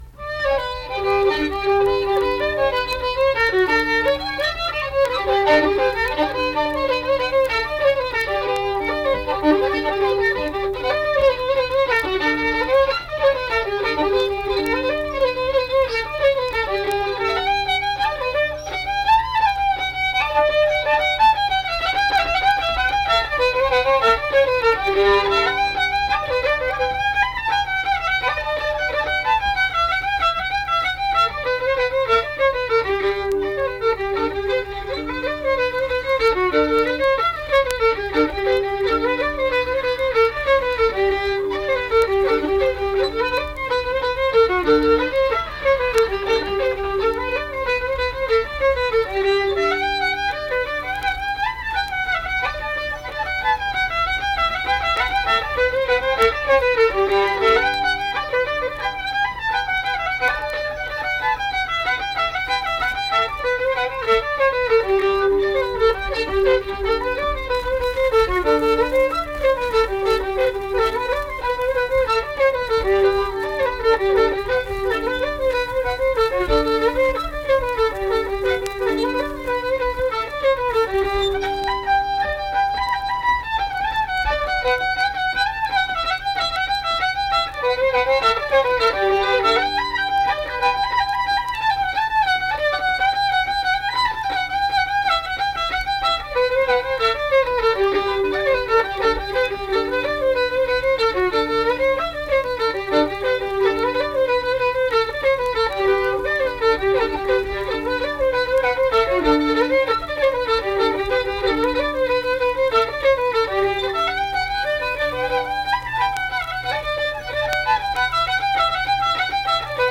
Accompanied guitar and unaccompanied fiddle music performance
Instrumental Music
Fiddle